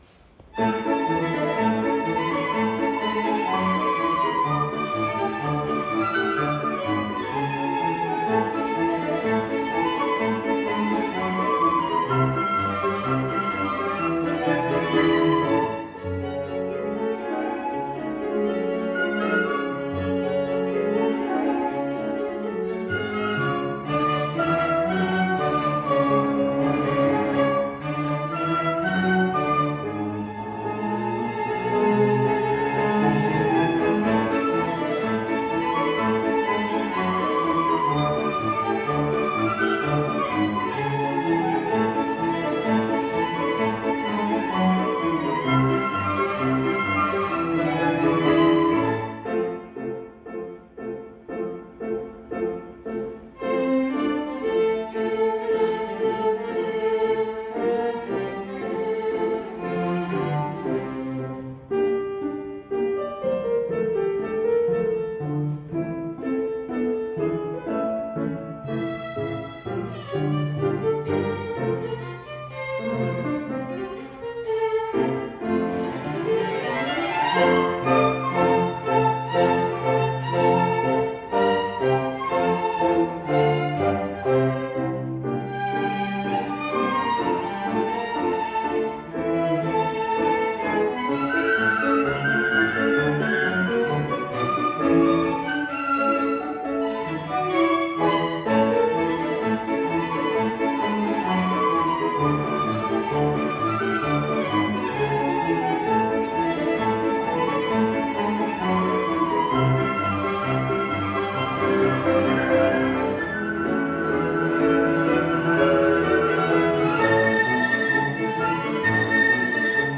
Flute, 2 Violins, Cello & Piano